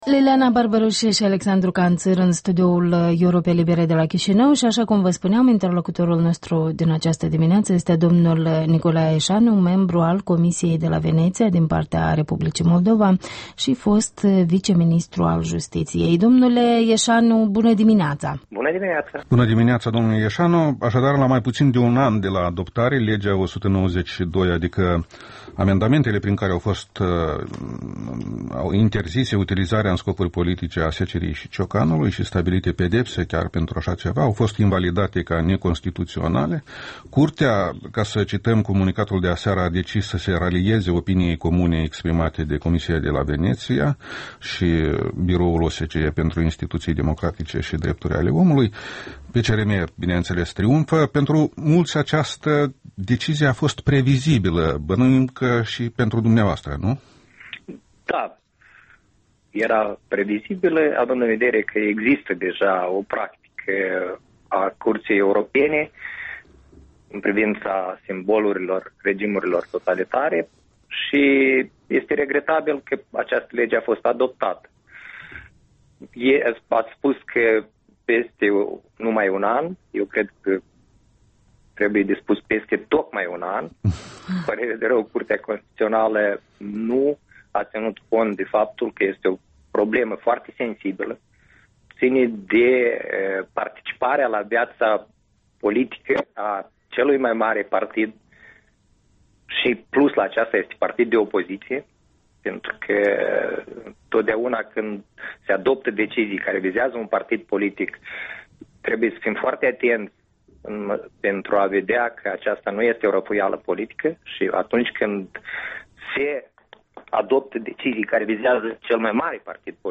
Interviul dimineții: cu Nicolae Eșanu, reprezentant al Moldovei în Comisia de la Veneția